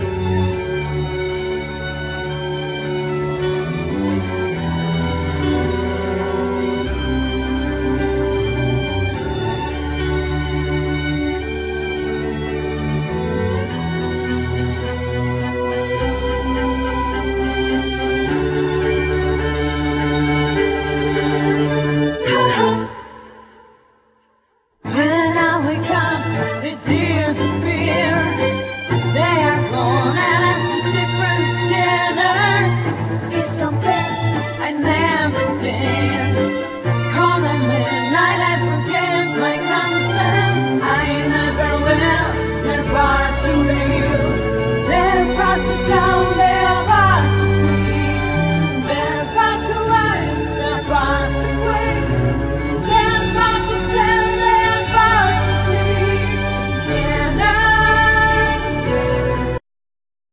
Guitar, Effect, Mix
Vocal, Piano, Programming
1st violin
2nd violin
Viola
Cello